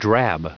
Prononciation du mot drab en anglais (fichier audio)
Prononciation du mot : drab